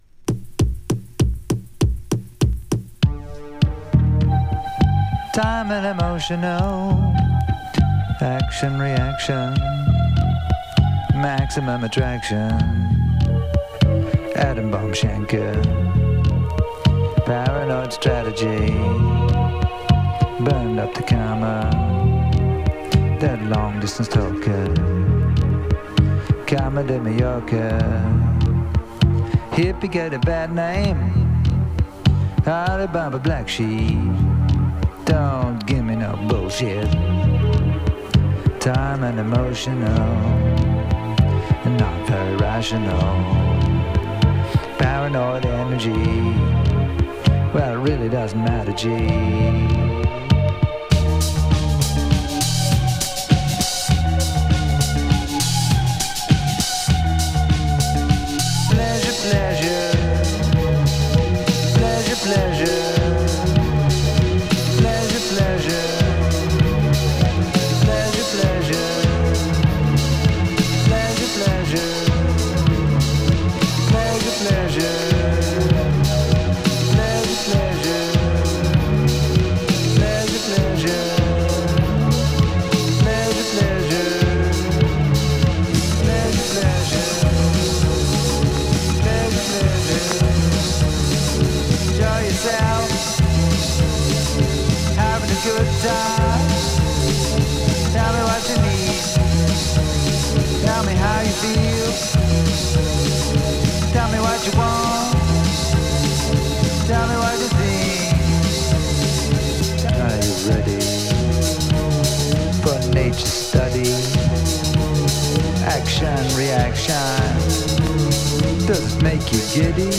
プログレッシブロック/カンタベリーシーンに分類され、実験的なグルーヴと即興性が特徴の作品。